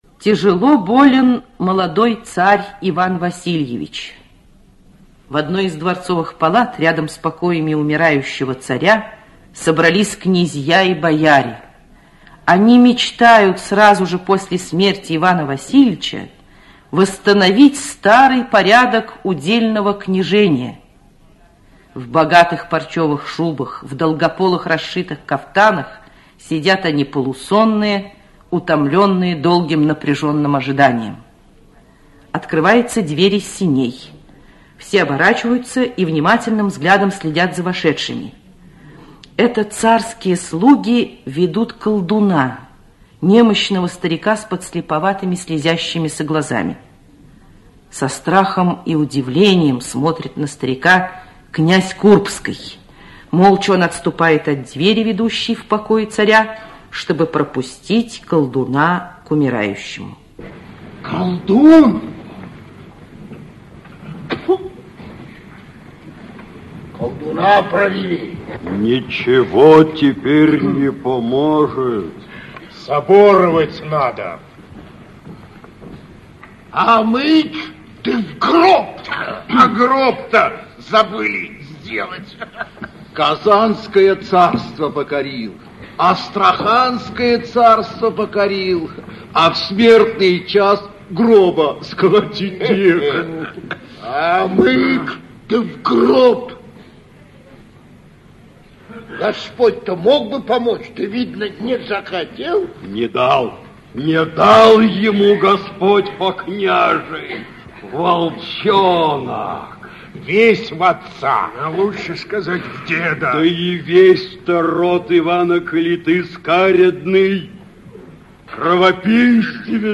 Аудиокнига Иван Грозный (спектакль) | Библиотека аудиокниг
Aудиокнига Иван Грозный (спектакль) Автор Алексей Толстой Читает аудиокнигу Анатолий Кторов.